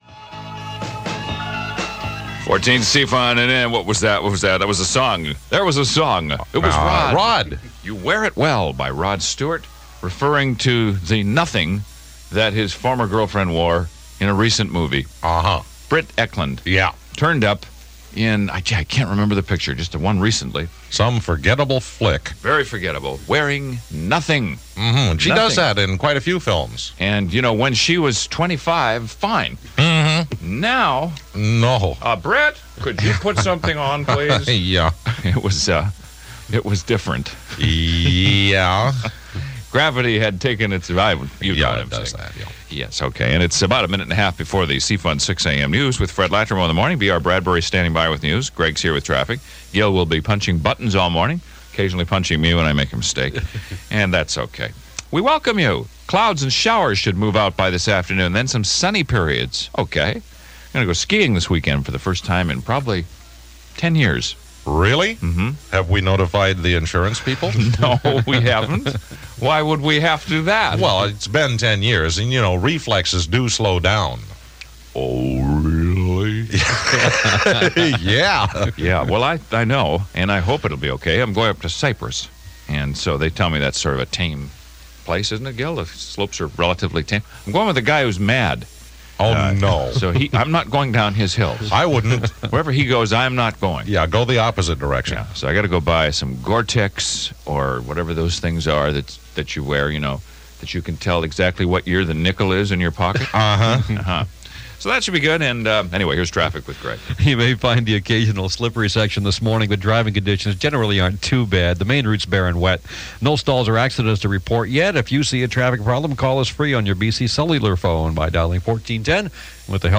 AIRCHECK OF THE WEEK